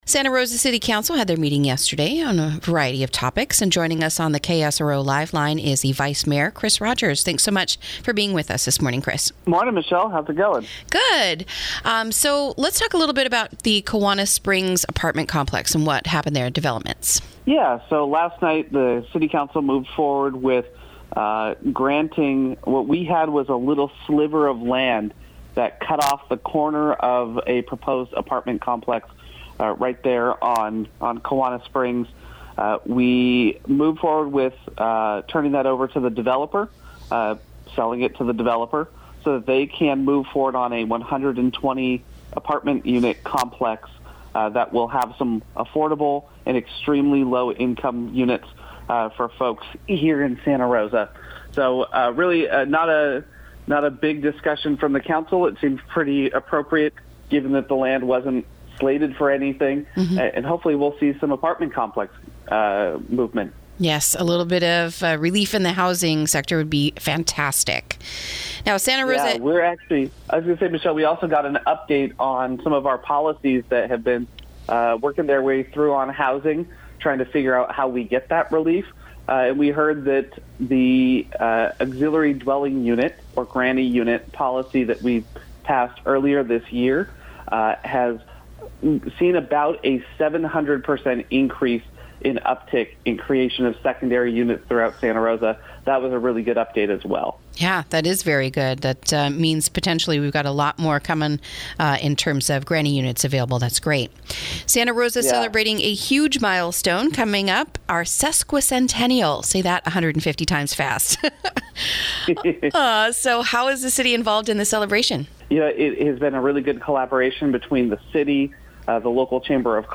INTERVIEW: Affordable Housing, Granny Units, and Fire Recovery Bills All Addressed in Santa Rosa Council Meeting